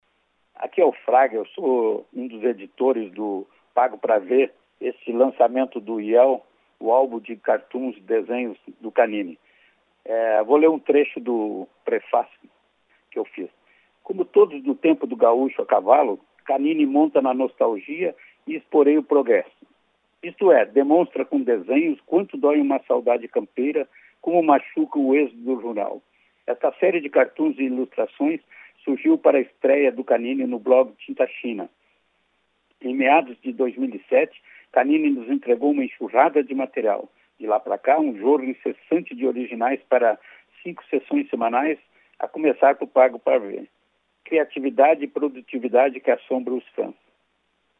O humorista e frasista